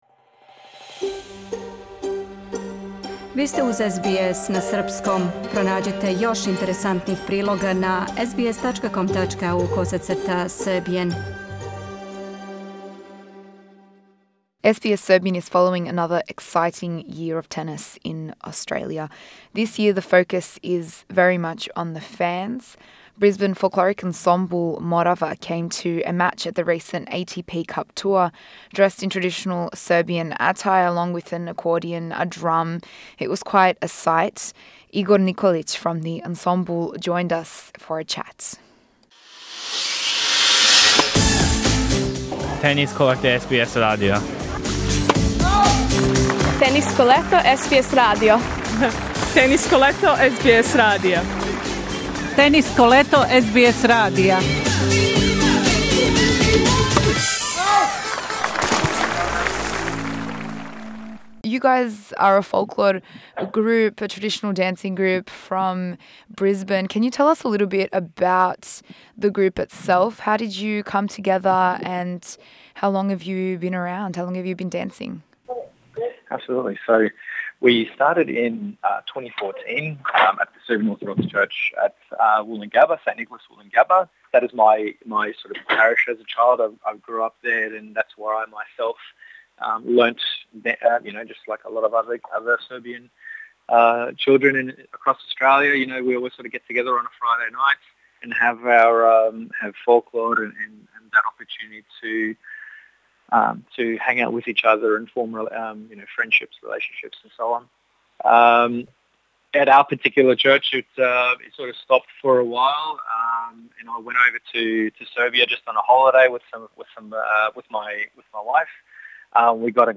The ATP Cup tour was just a taste of what's to come. SBS Serbian caught with the fans who rocked traditional outfits in Brisbane's January heat.